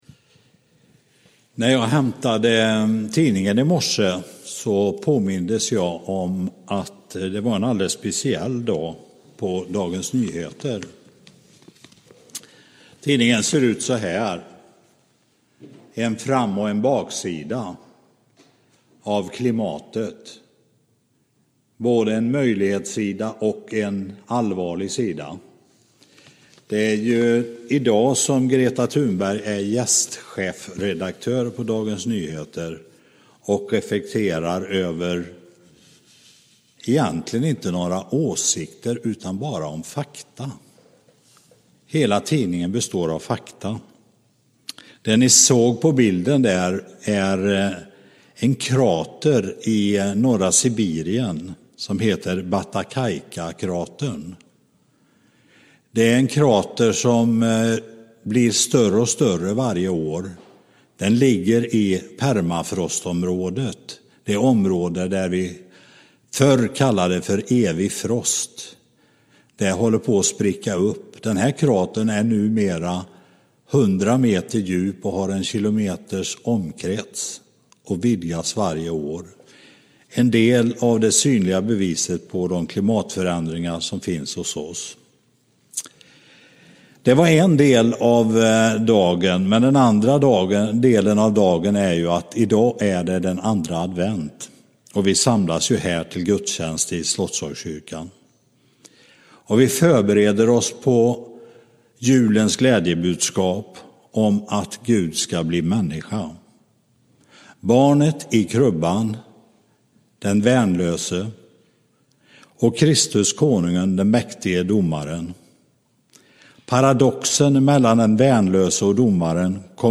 podcast-predikan-6-dec-2020